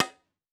Cue Strike Intense.wav